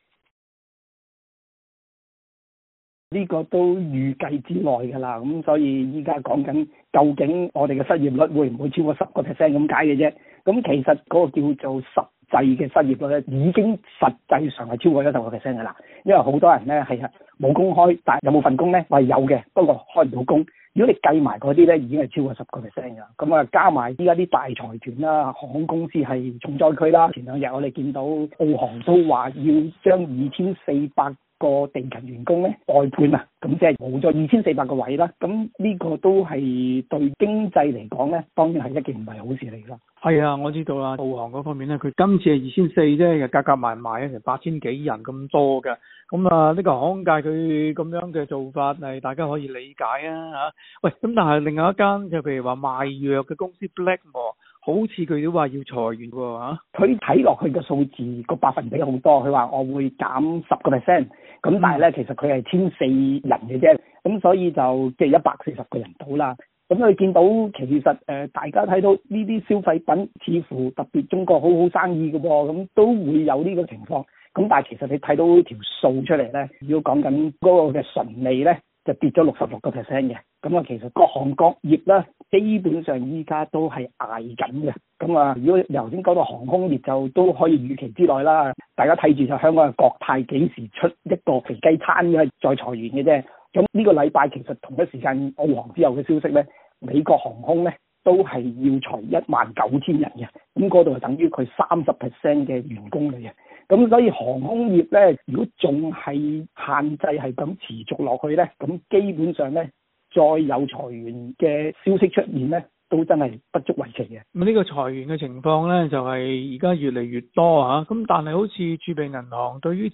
详情请收听余下的访问。